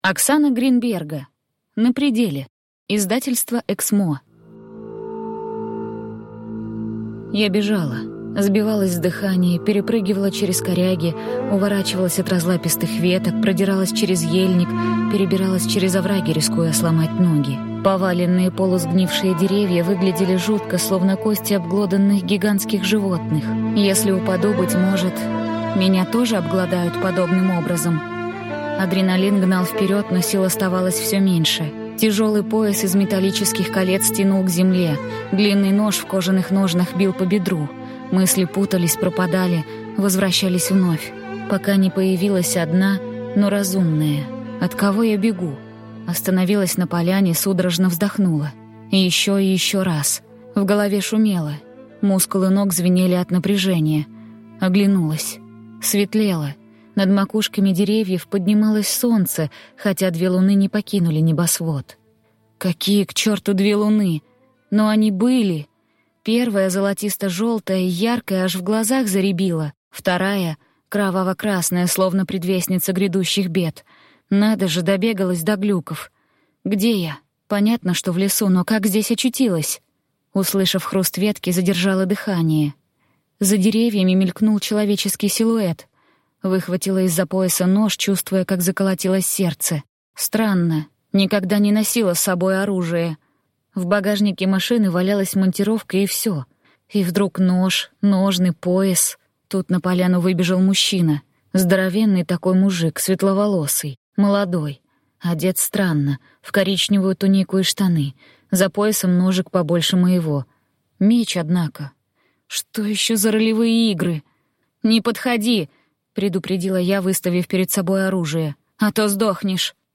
Aудиокнига На пределе